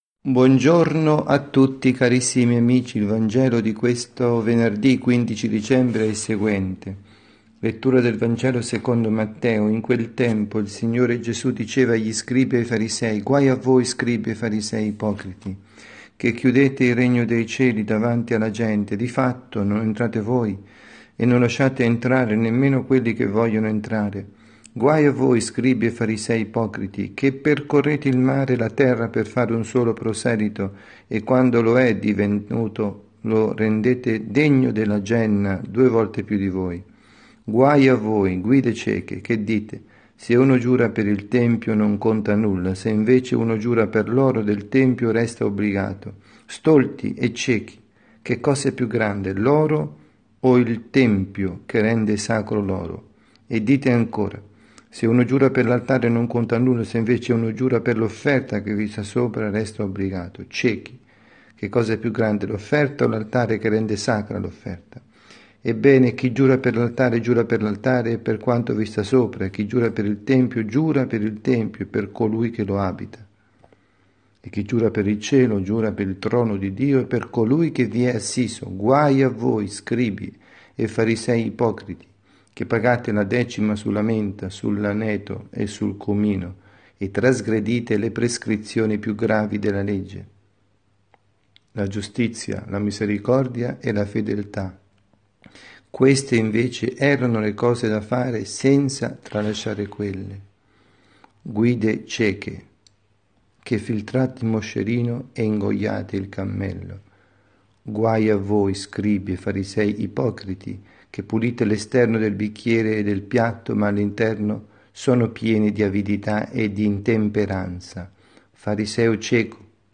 Omelia
dalla Parrocchia S. Rita –  Milano